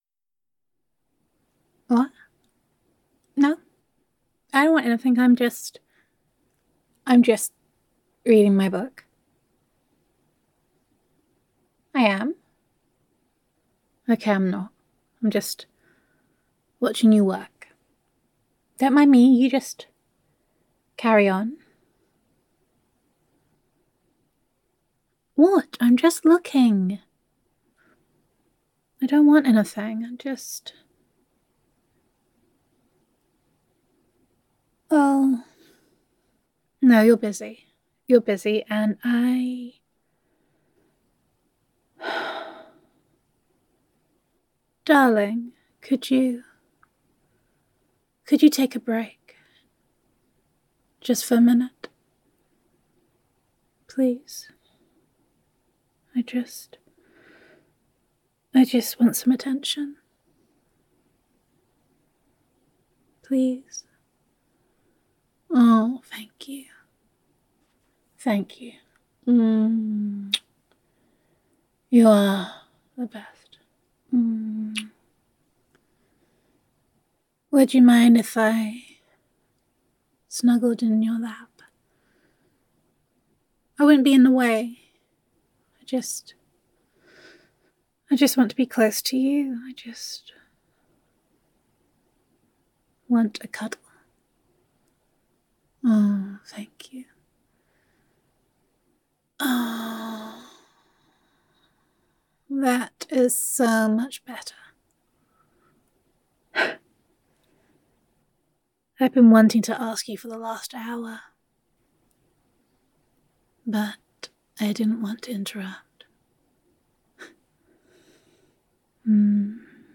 [F4A] Being Cheeky [Snuggling in Your Lap][Cuddlesome][Warm][Gender Neutral][Needy Girlfriend Wants Cuddles and Pets]